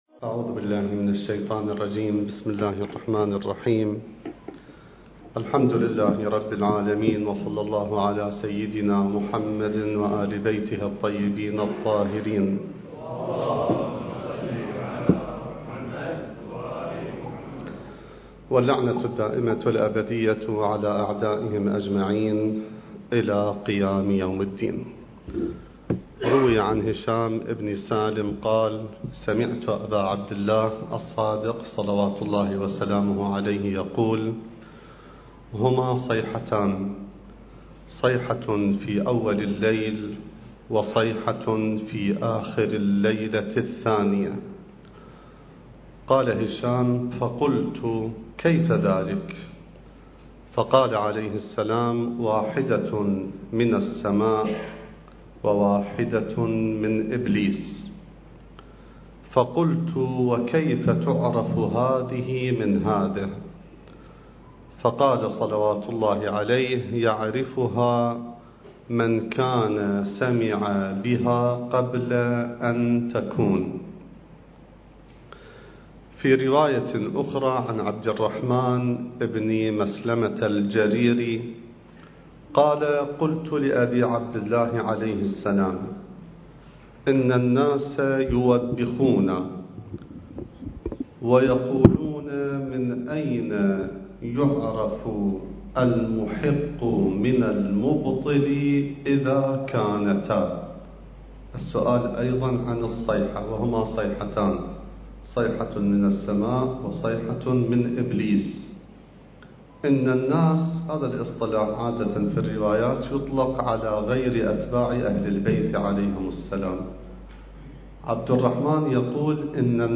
المكان: مركز خاتم الأوصياء (عجّل الله فرجه)/ جامع الرسول (صلّى الله عليه وآله) / بغداد